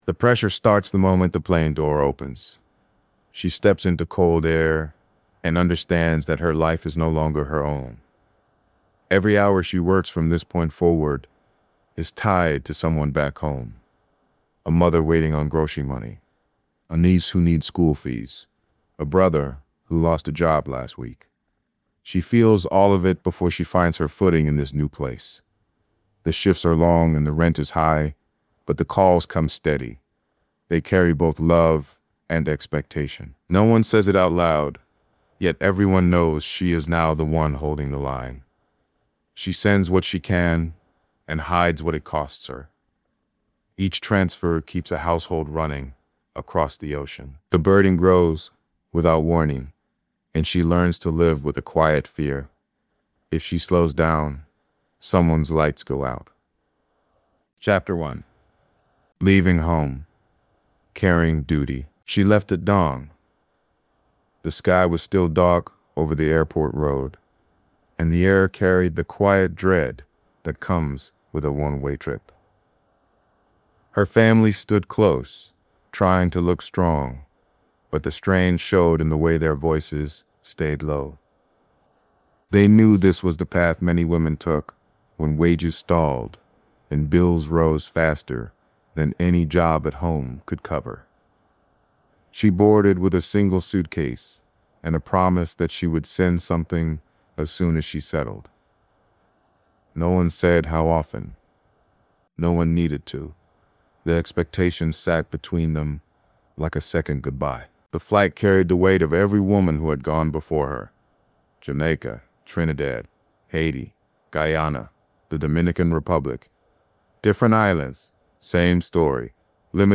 This hardline documentary narrative follows the silent weight carried by Caribbean women who migrate abroad and become the financial lifeline for families back home. Through a tense, grounded portrayal of sacrifice, duty, and emotional strain, the story reveals how remittances transform from loving support into an unspoken burden that consumes their health, time, and identity.